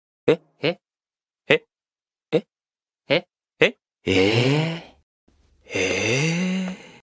Efek suara Eh?eh?ehhhh?
Kategori: Suara viral
Coba sound effect meme 'eh eh ehhh?' yang lucu dan menghibur!
efek-suara-ehehehhhh-id-www_tiengdong_com.mp3